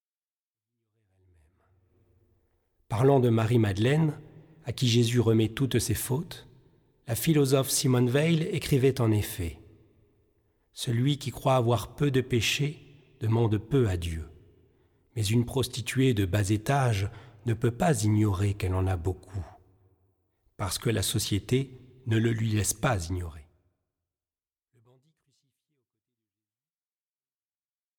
méditations spirituelles